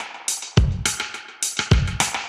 Index of /musicradar/dub-designer-samples/105bpm/Beats
DD_BeatA_105-01.wav